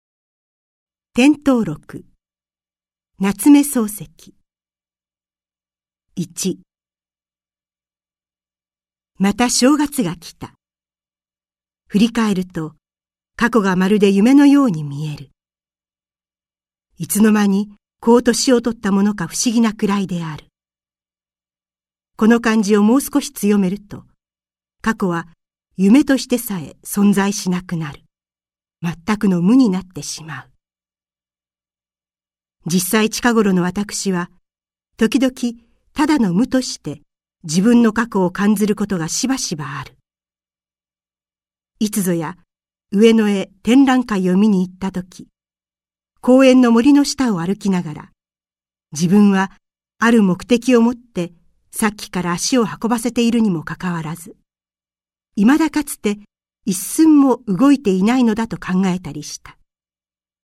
朗読ＣＤ　朗読街道57「点頭録」夏目漱石
朗読街道は作品の価値を損なうことなくノーカットで朗読しています。